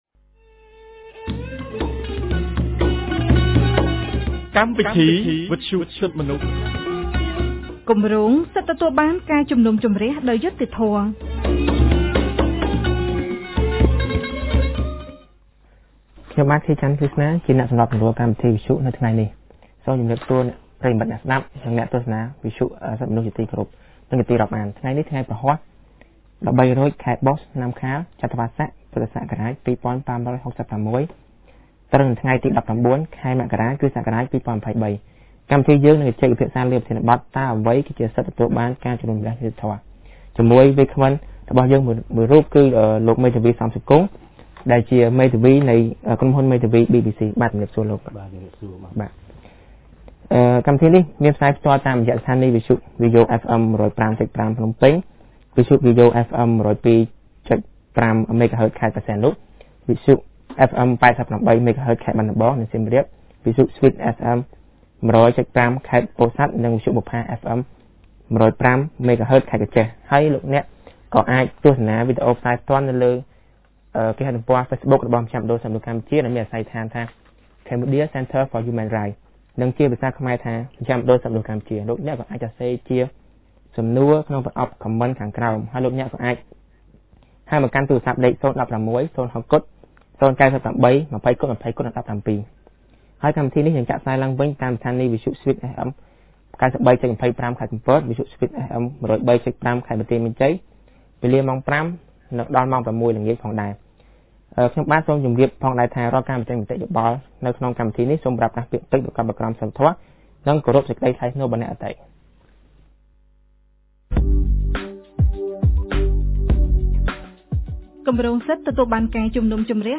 On Tuesday 19 January 2023, CCHR’s Fair Trial Rights Project (FTRP) held a radio program with a topic on What is Fair Trial Rights?